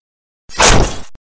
دانلود صدای ساتور از ساعد نیوز با لینک مستقیم و کیفیت بالا
جلوه های صوتی